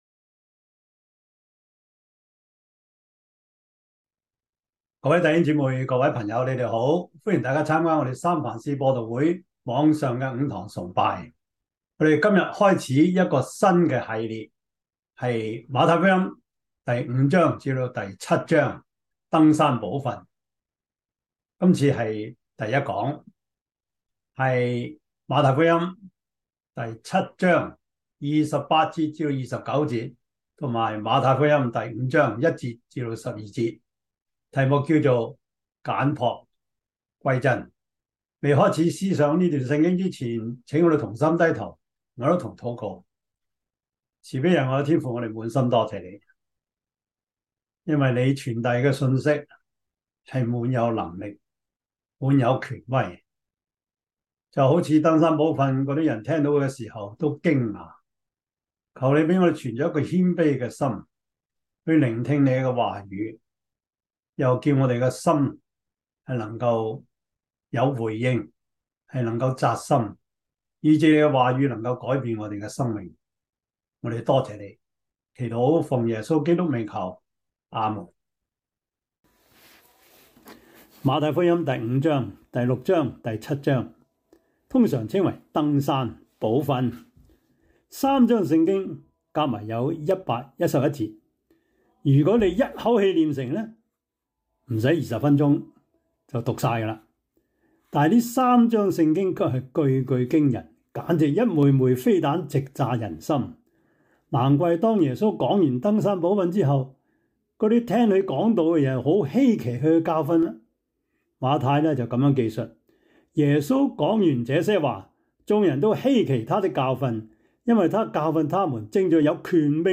Service Type: 主日崇拜
馬太福音 7:28 Chinese Union Version (Traditional) 28 耶 穌 講 完 了 這 些 話 ， 眾 人 都 希 奇 他 的 教 訓 ； Topics: 主日證道 « 快樂人生小貼士 – 第十三課 喜樂的泉源 »